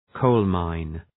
Προφορά
{‘kəʋlmaın}